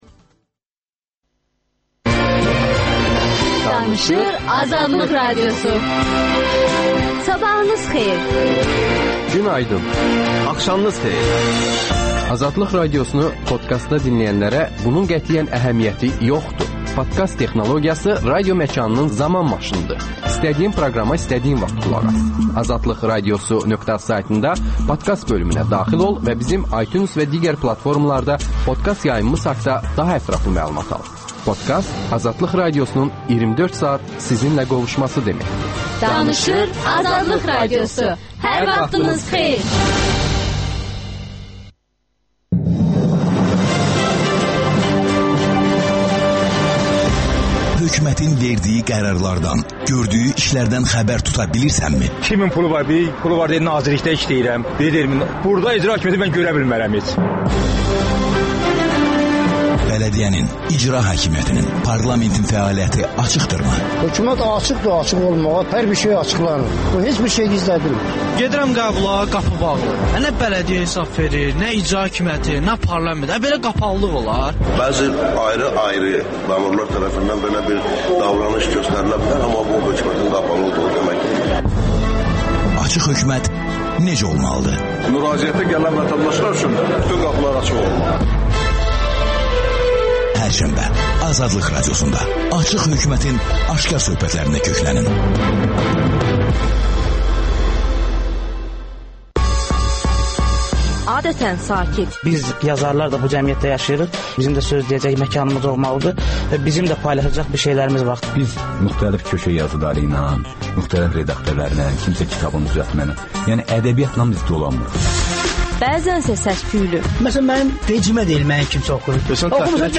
Şairlə söhbət